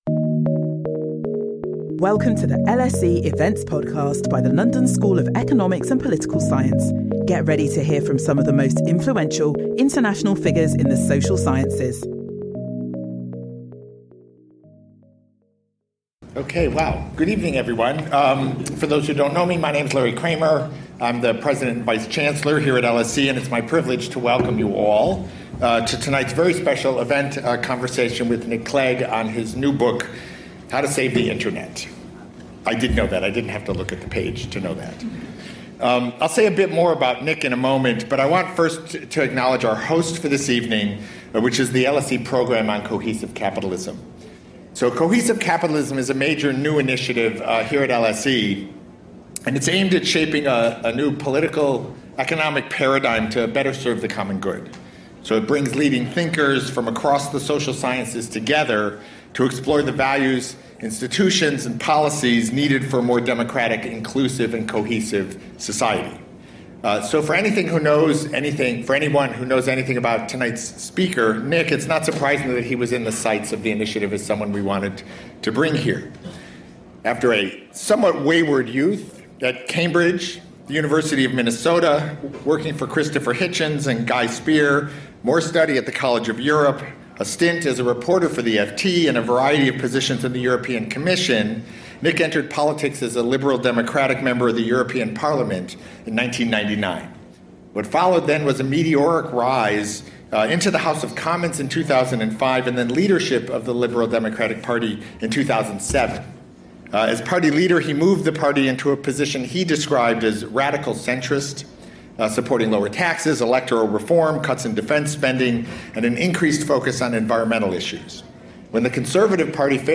Join us for this special event where former British Deputy Prime Minister Nick Clegg will talk about his new book, How to Save the Internet.